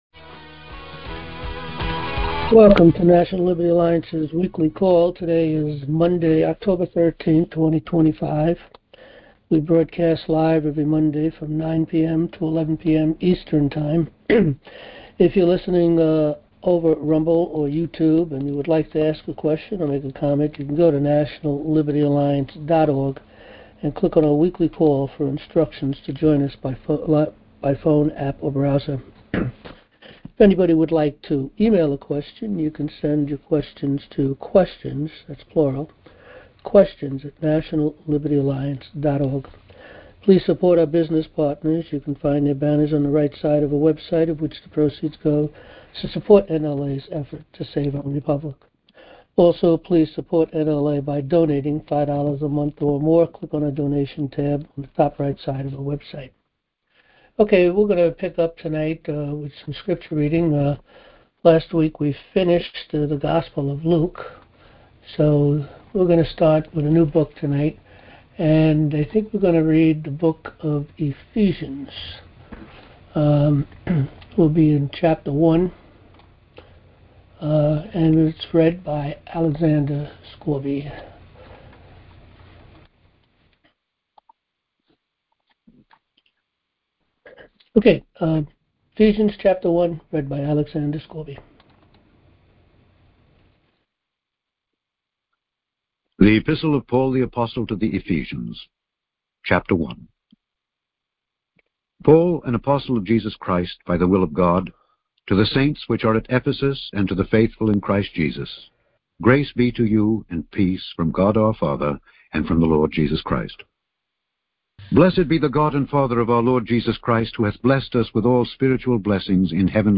Join our Live Monday Night Open Forum | National Liberty Alliance